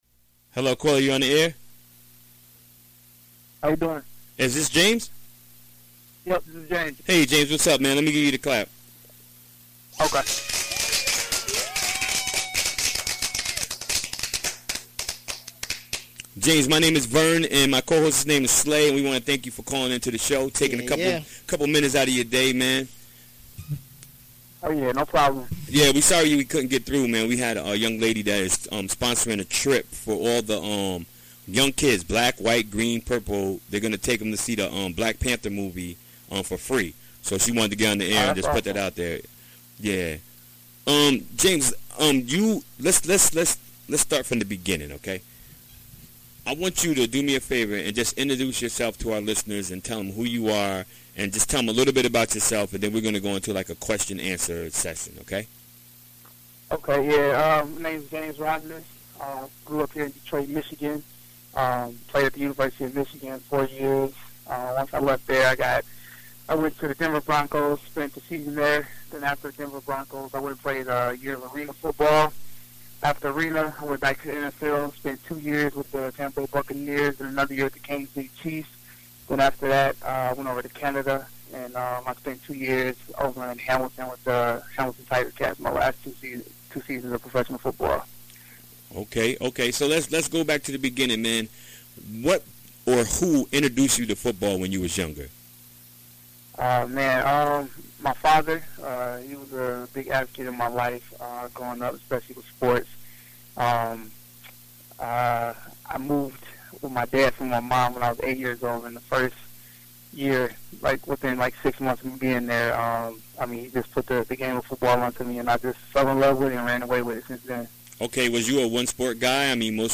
Recorded during the WGXC Afternoon Show Wednesday, February 21, 2018.